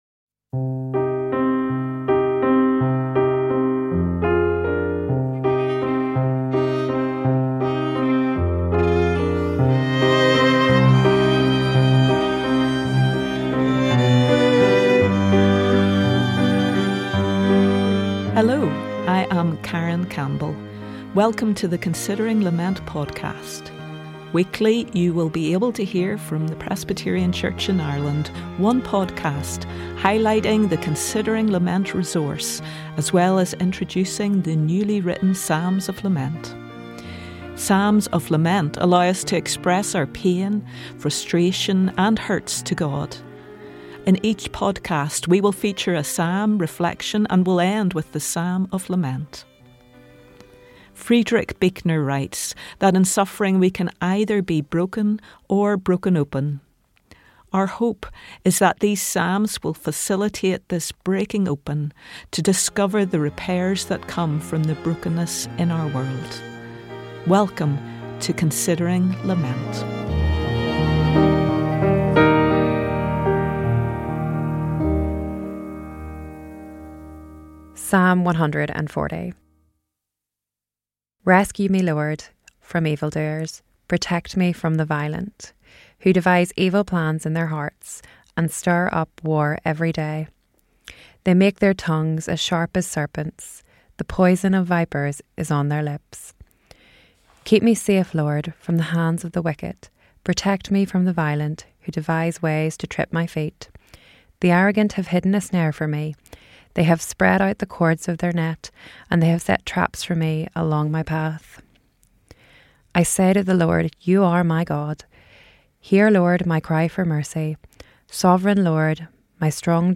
The episode begins with a reading of the psalm, grounding us in its raw honesty and plea for deliverance.
The episode concludes with a newly composed psalm of lament, inspired by Psalm 140, giving voice to contemporary struggles while holding onto hope for healing and restoration. Thoughtful and contemplative, this short podcast invites listeners to pause, reflect, and consider what reconciliation can look like in their own lives today.